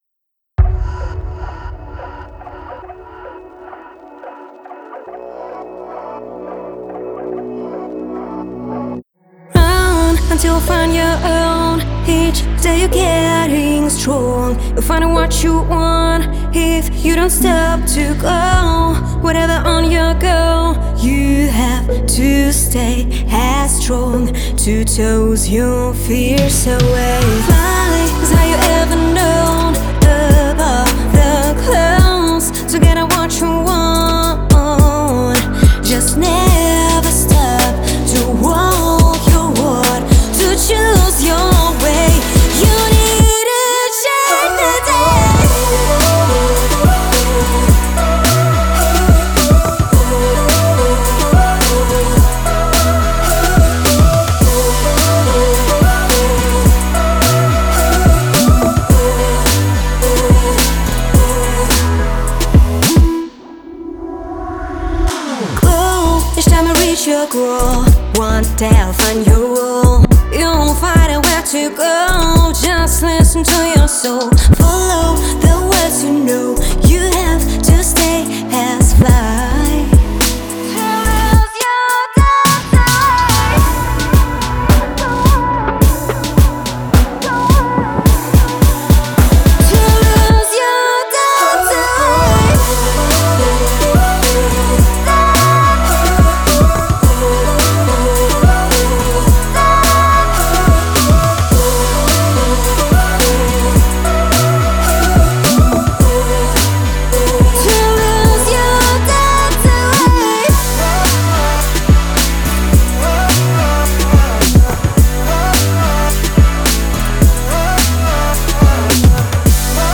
это трек в жанре инди-поп